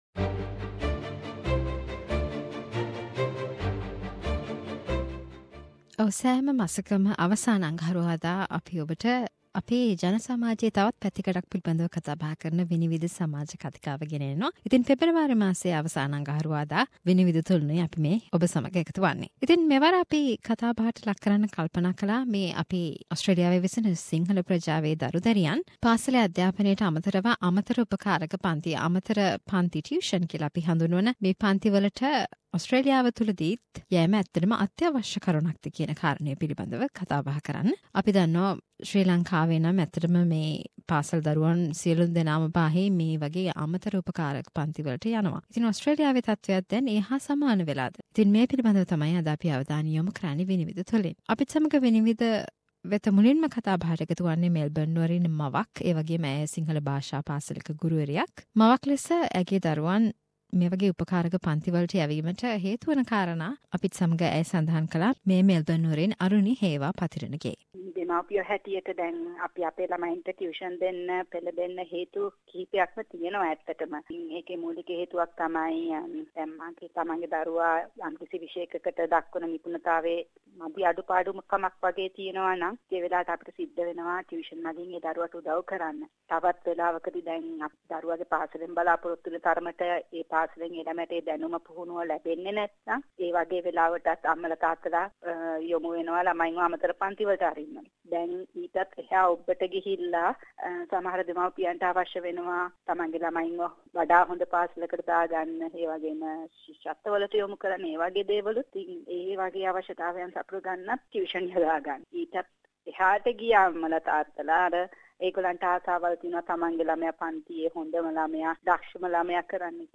SBS Sinhalese monthly discussion Vinivida on extra tutoring for school kids.